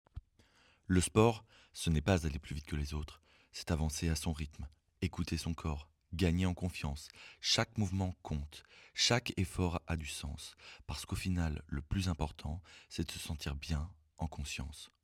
Voix off sport